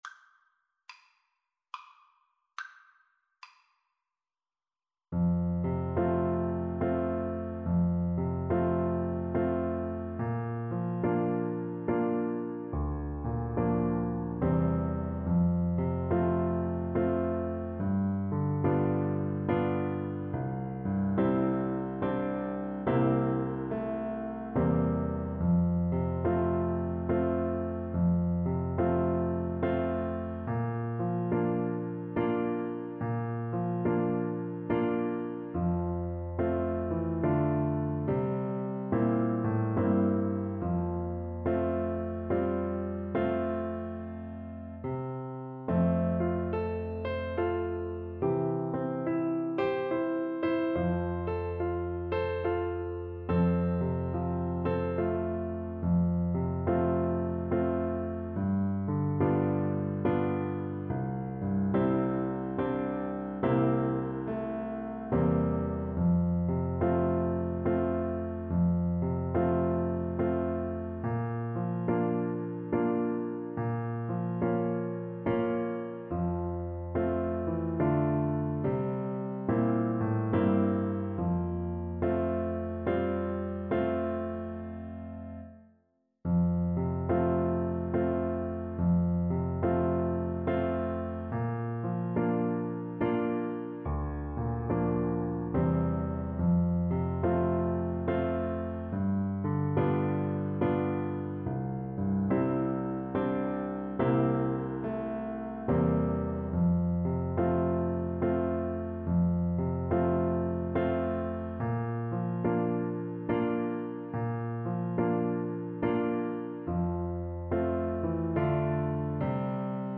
classic western song
With a swing = 100
3/4 (View more 3/4 Music)